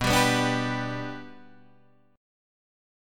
Bm#5 chord